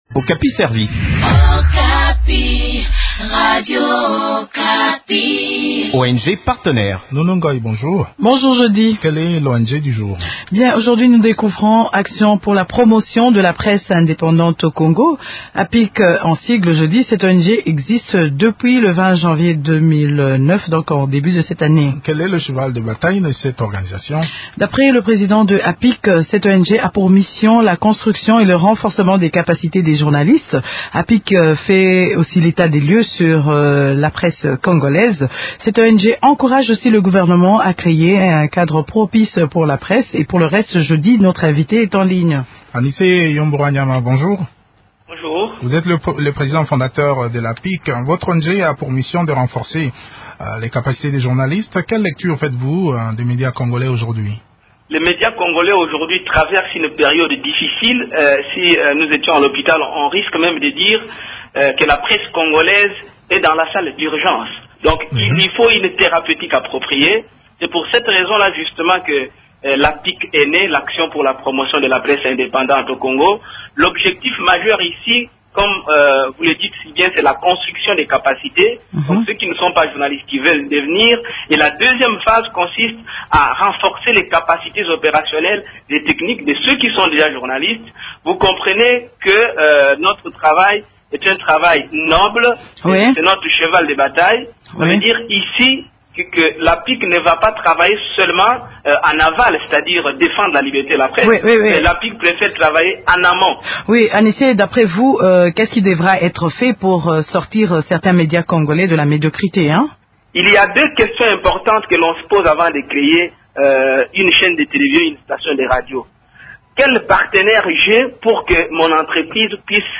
s’entretiennent sur les activités de cette ONG